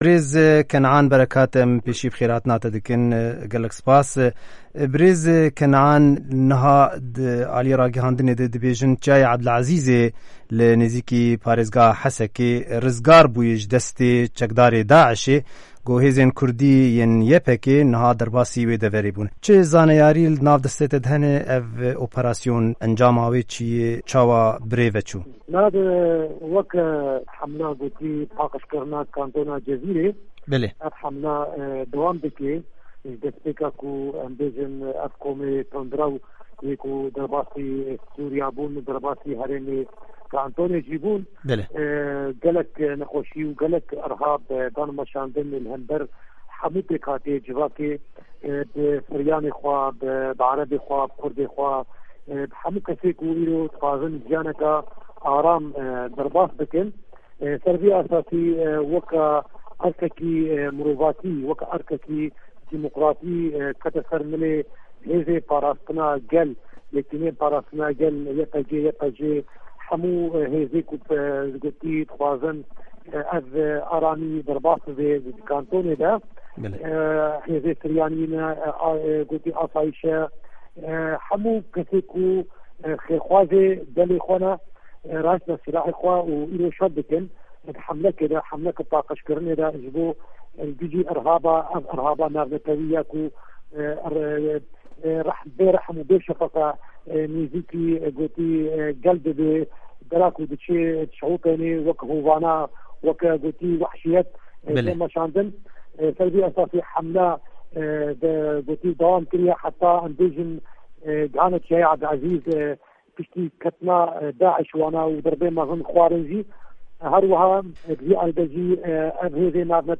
Di hevpeyvîna Dengê Amerîka de Kenan Berekat, Berpirsê Karê Hindur yê Kantona Cizîre li ser azadkirina Çîyayê Ebdulazîz agahîyan dide.